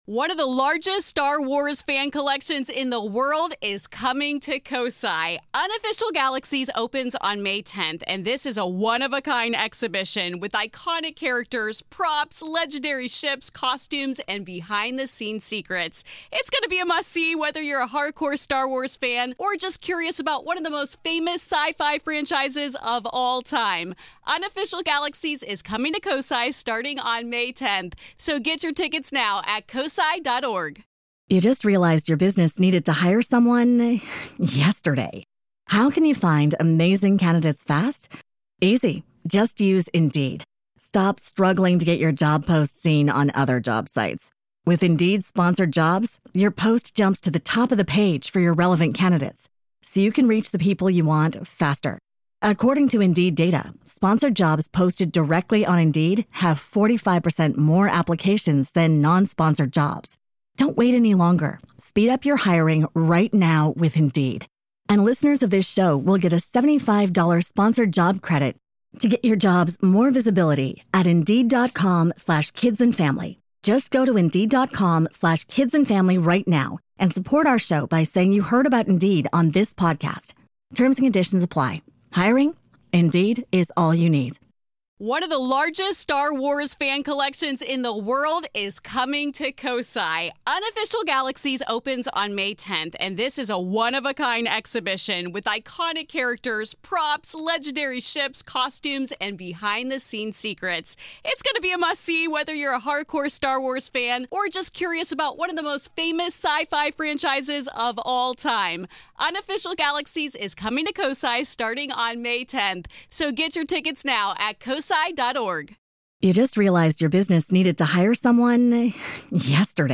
Join us as we examine whether trauma leaves a louder imprint and why some corners of Gettysburg refuse to stay silent. This is Part Two of our conversation.